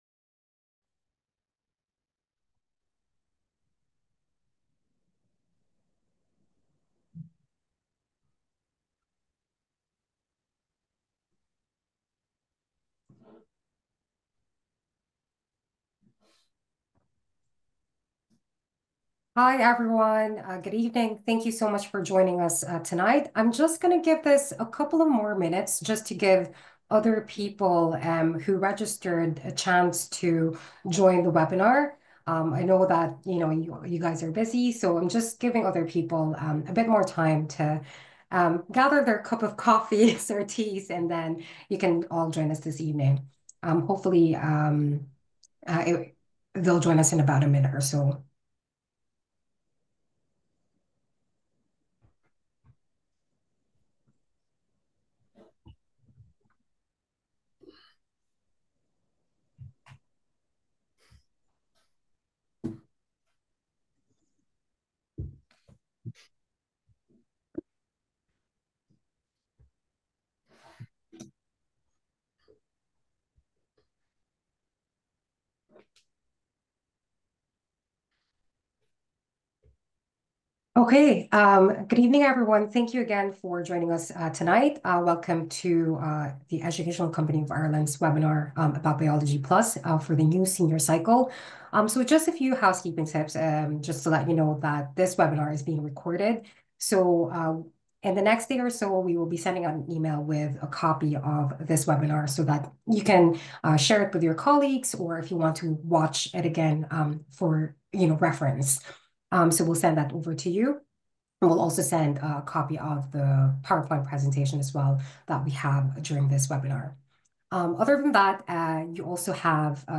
WEBINARS